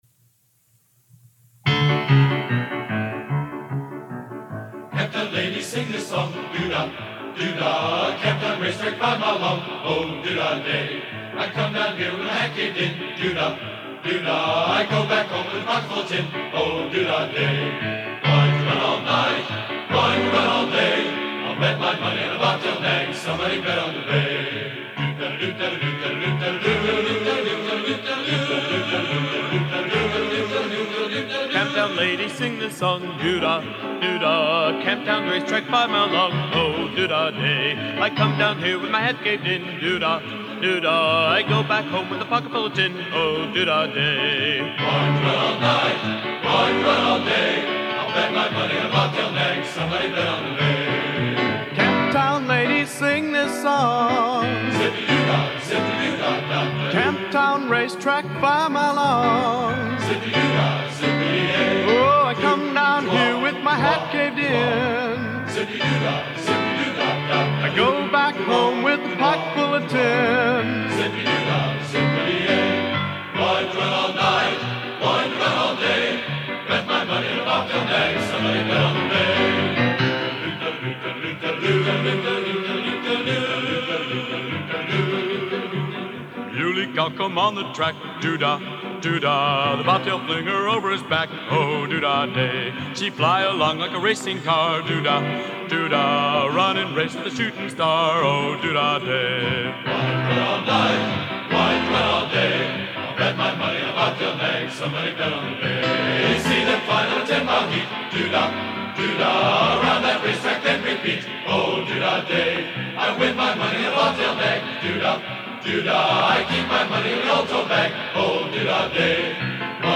Genre: Traditional | Type: Studio Recording